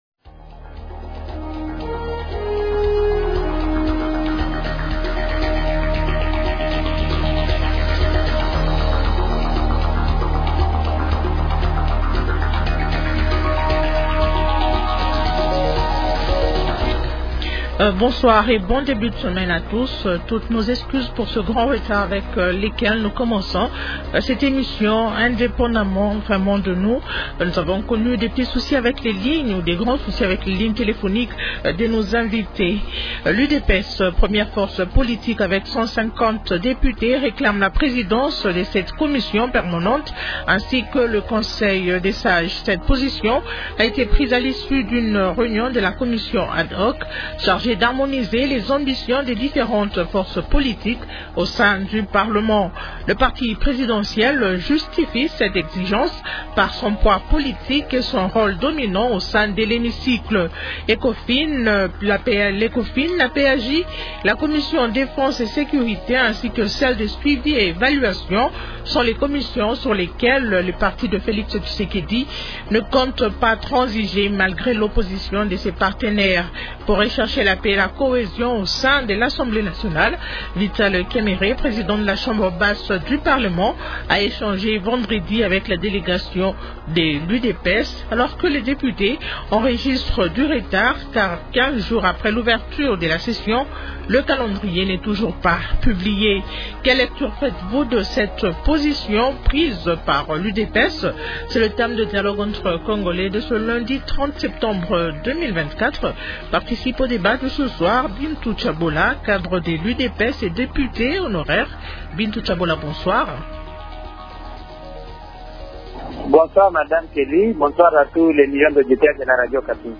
Question : -Quelle lecture faites-vous de cette position de l’UDPS ? Invités : -Gary Sakata, député national élu de Bagata dans la province du Kwilu.